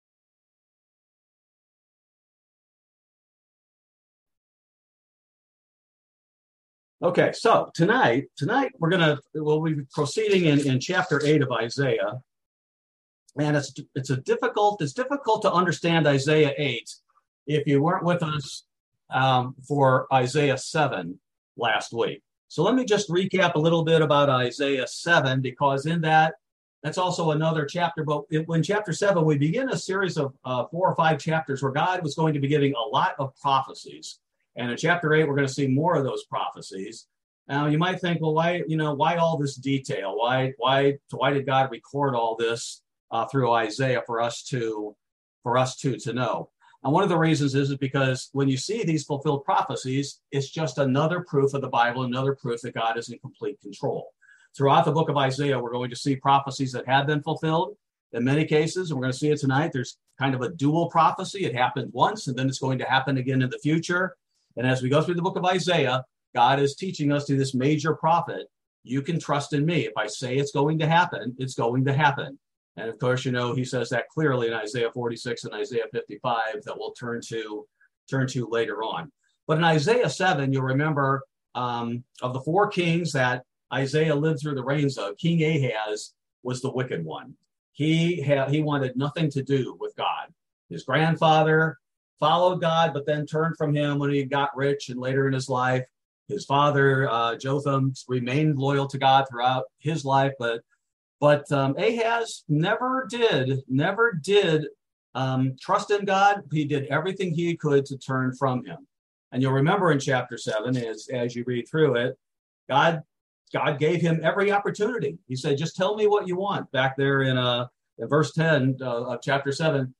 Bible Study: August 10, 2022